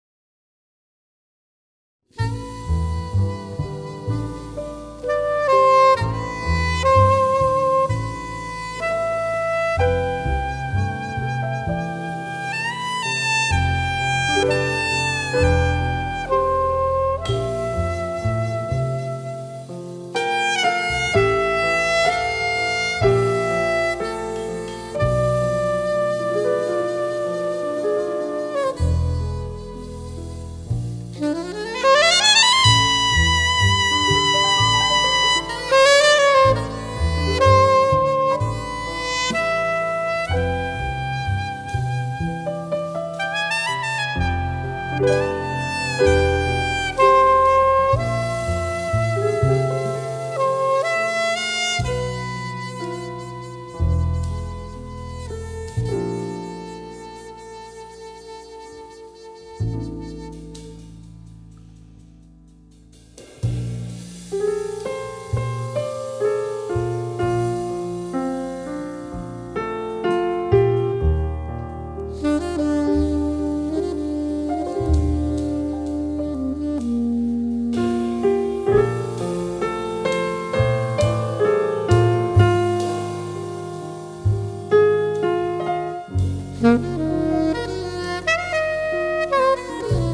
klavir
saksafoni
kontrabas
bobni